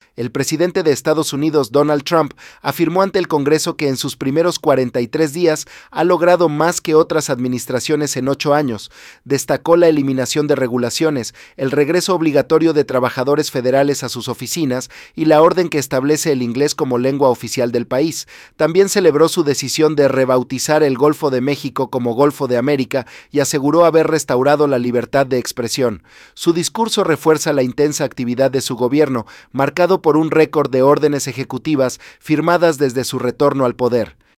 El presidente de Estados Unidos, Donald Trump, afirmó ante el Congreso que en sus primeros 43 días ha logrado más que otras administraciones en ocho años. Destacó la eliminación de regulaciones, el regreso obligatorio de trabajadores federales a sus oficinas y la orden que establece el inglés como lengua oficial del país.